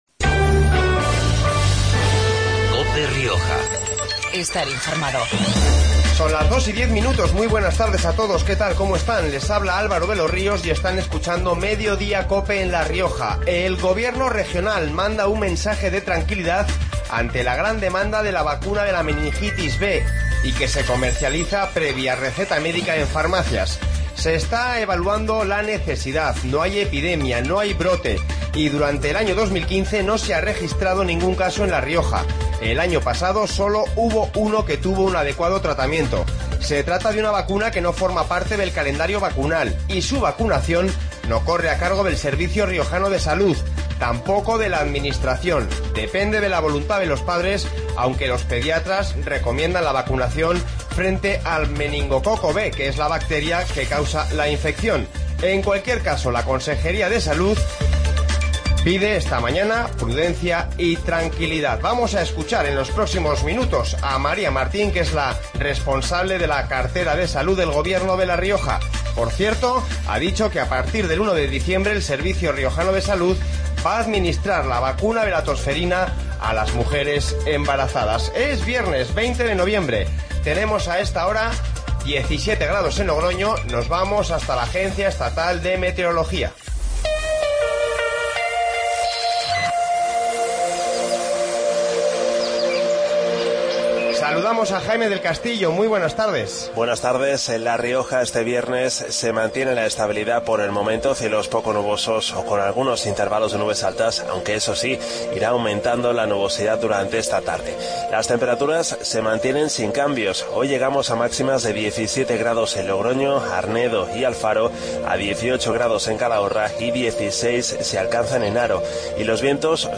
Informativo Mediodia en La Rioja 20-11-15